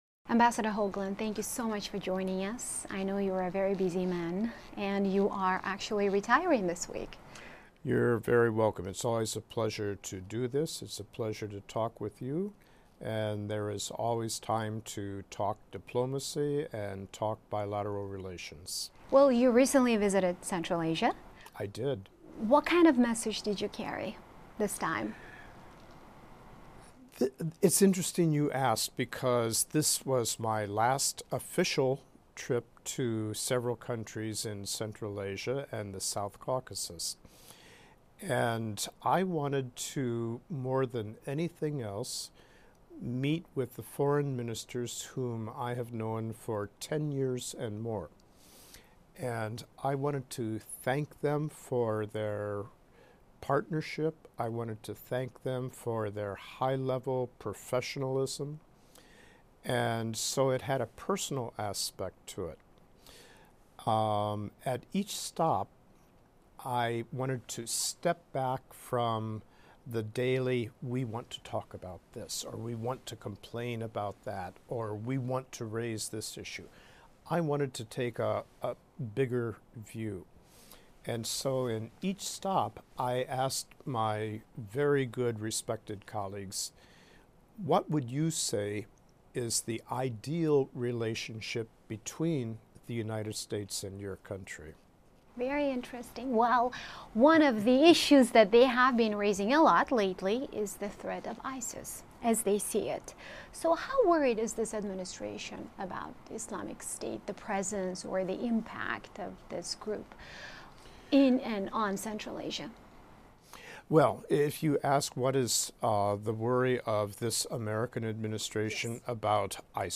US-Central Asia: Ambassador Richard Hoagland talks to VOA Uzbek